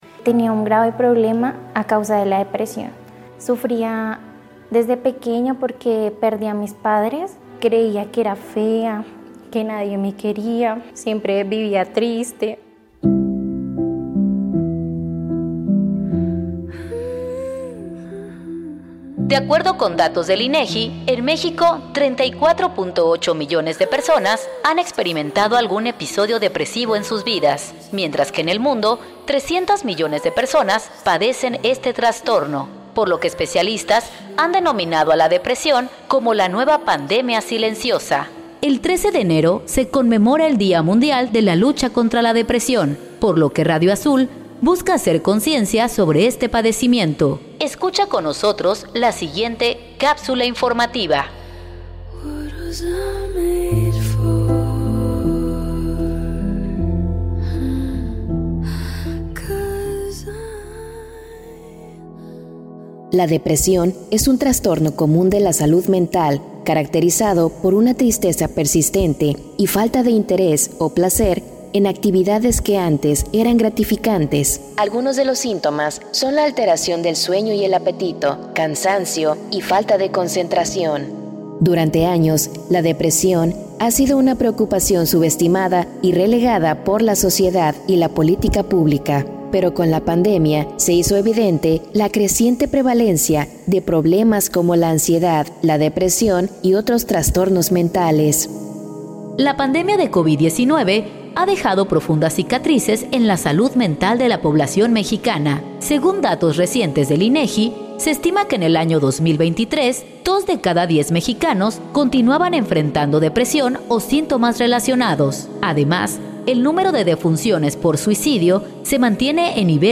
Escucha con nosotros la siguiente cápsula informativa.